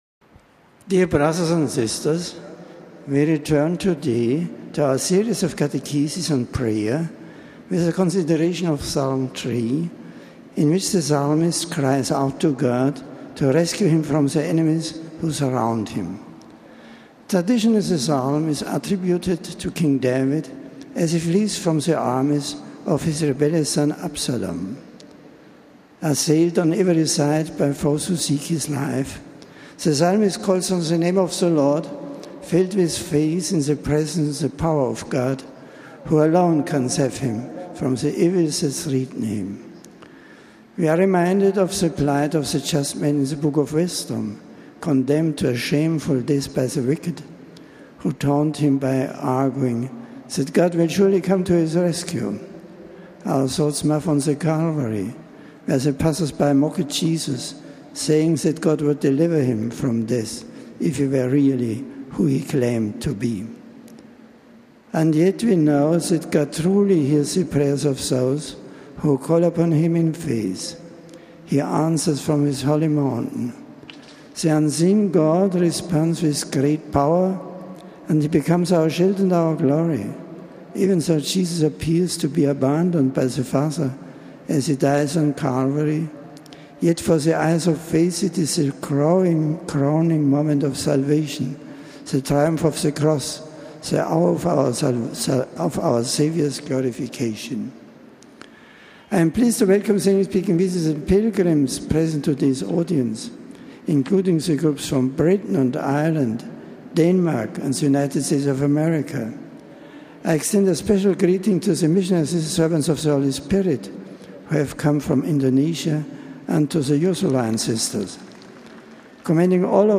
The general audience of Sept. 7 was held in the open in St. Peter’s Square. A scripture passage was read in several languages. An aide greeted the Pope on behalf of the English-speaking pilgrims, and presented the various groups to him. Pope Benedict then delivered a discourse in English.